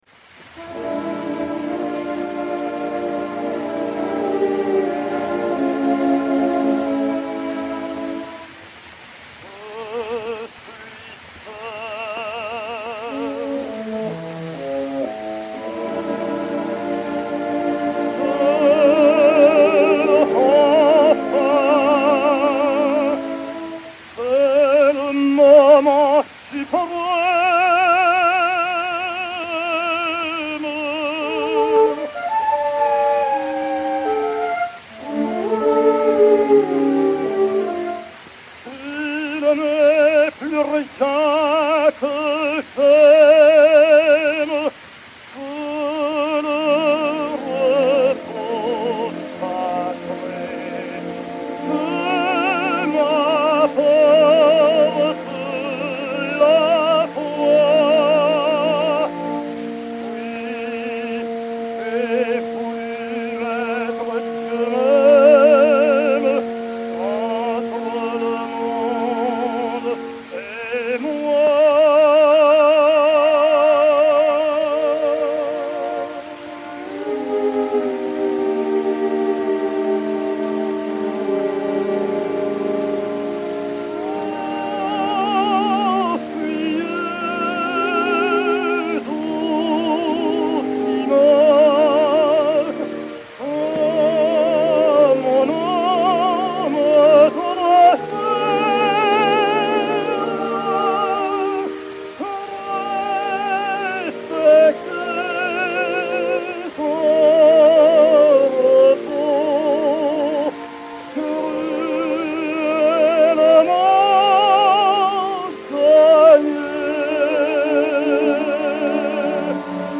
Léon David (Des Grieux) et Orchestre
XP 4292, enr. à Paris en 1908